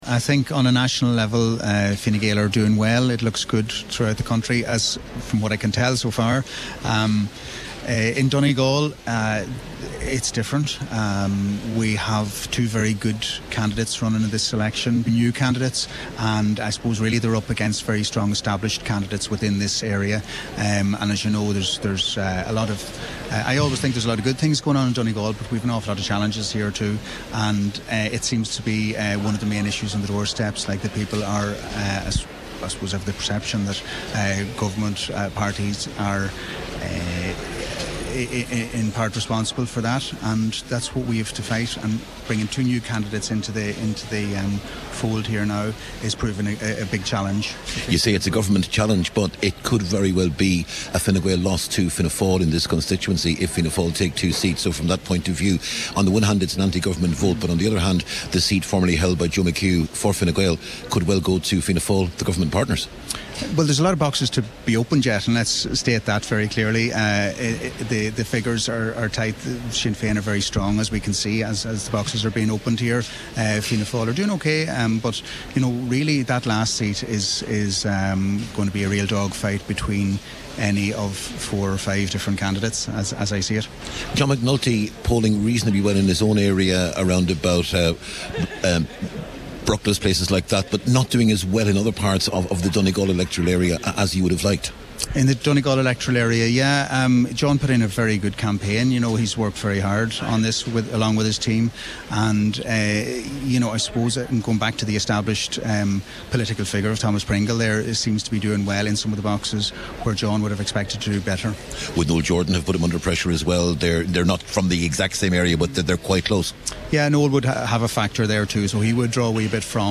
Former Fine Gael Councillor Barry Sweeney is part of Senator Bradley’s campaign team. He says introducing two new candidates was a challenge: